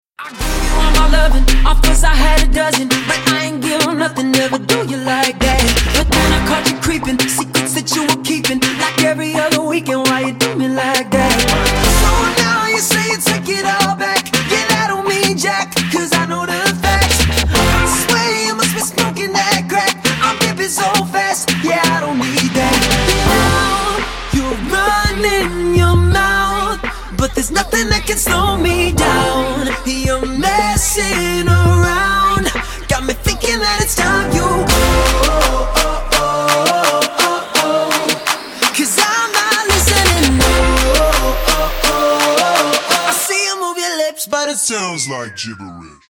• Качество: 160, Stereo
поп
мужской вокал
dance